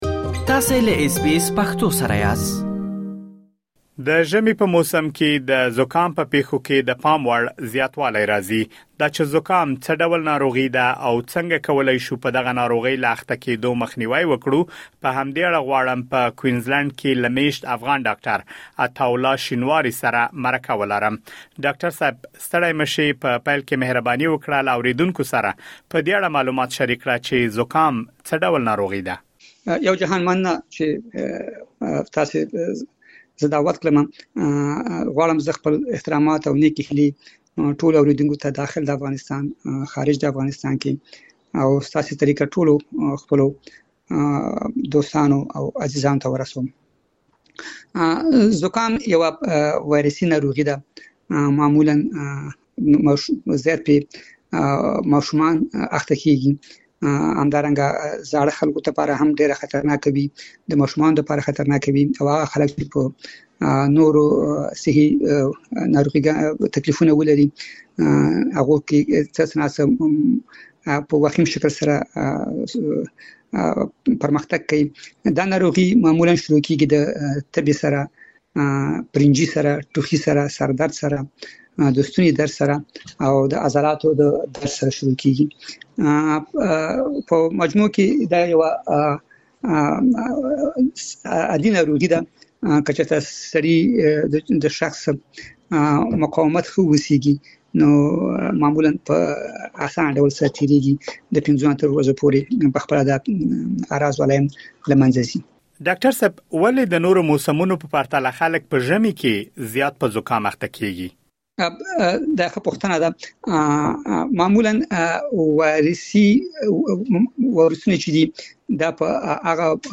مهرباني وکړئ لا ډېر معلومات په ترسره شوې مرکې کې واورئ.